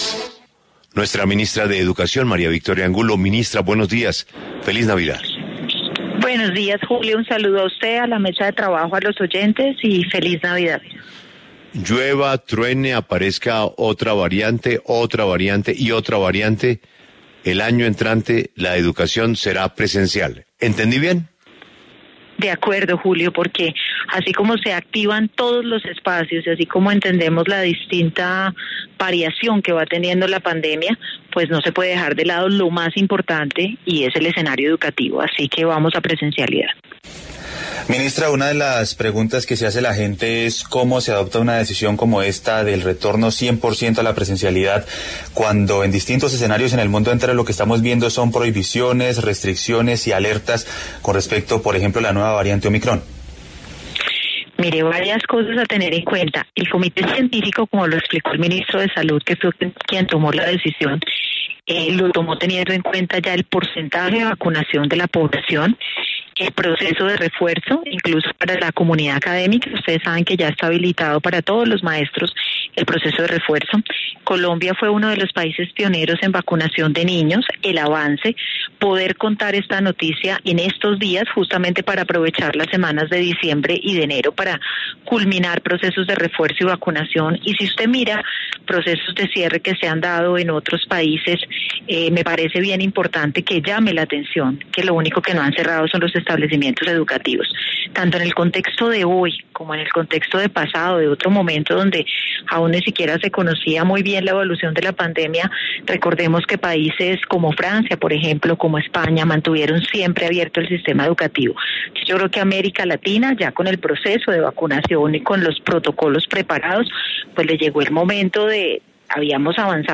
En La W, la ministra de Educación, María Victoria Angulo, pidió a alcaldes y gobernadores apoyar la implementación de las directrices entregadas por el Gobierno Nacional.